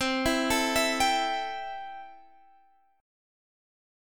C6 Chord (page 4)
Listen to C6 strummed